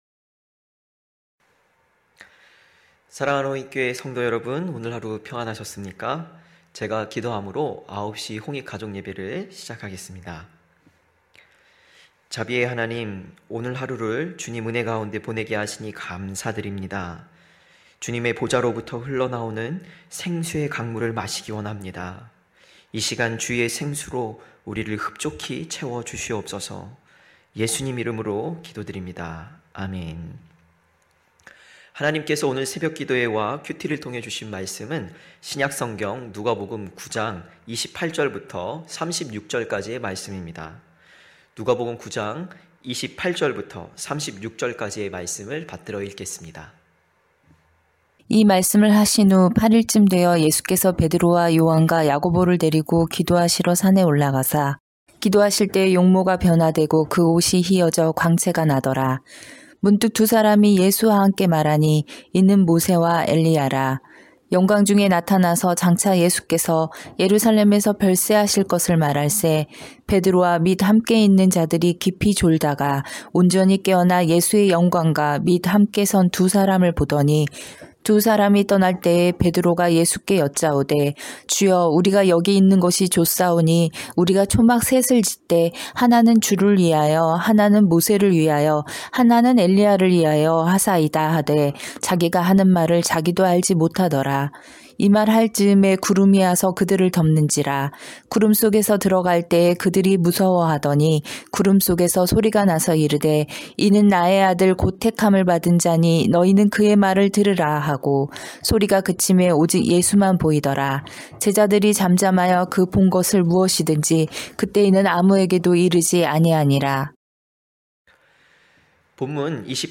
9시홍익가족예배(1월28일).mp3